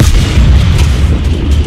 mbtfire5.ogg